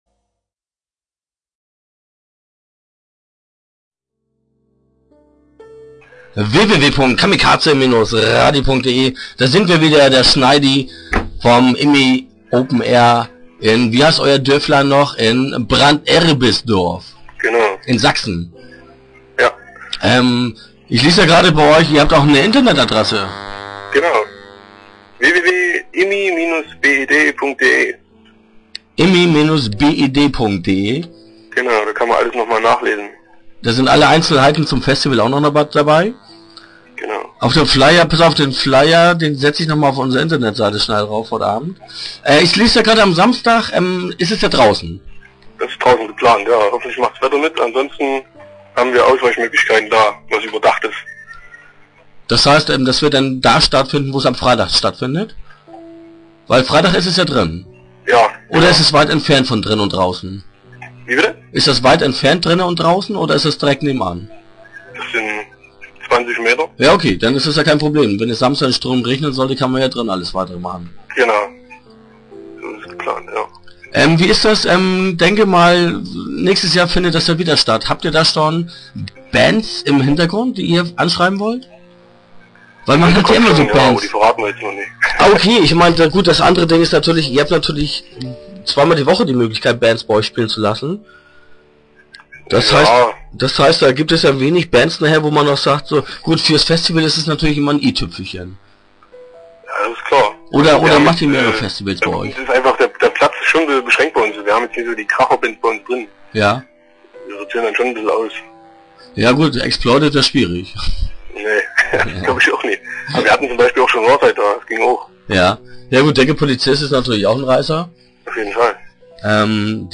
Start » Interviews » IMI Open Air 2008